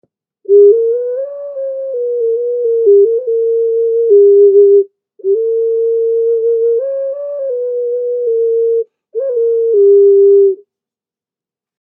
Hawk Ocarina Sound Healing Instruments Black Clay Pottery
This Instrument produces a lovely melody.
A recording of the sound of this particular ocarina is in the top description, just click on the play icon to hear the sound.
This musical instrument  is 5 inches by 3 inches across and 2 inches tall. It weighs almost 1 pounds, a cord can be added to make into necklace, has four key holes